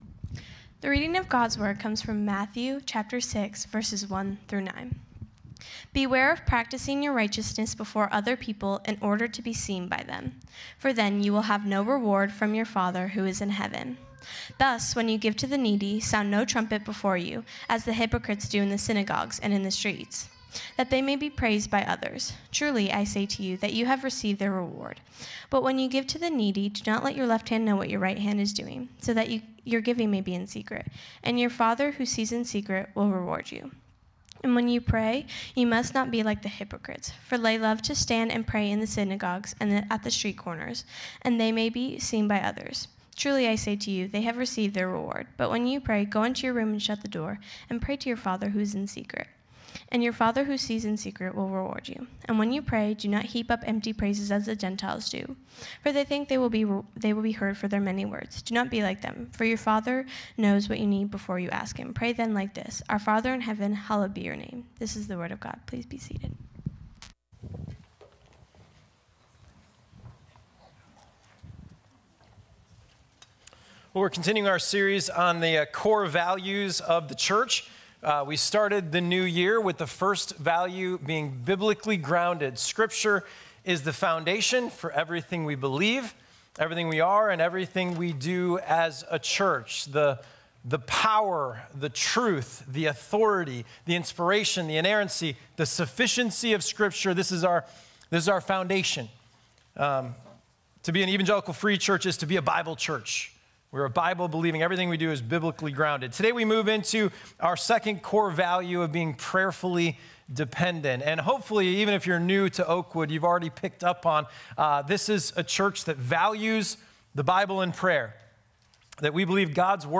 1.30.22-sermon-audio.m4a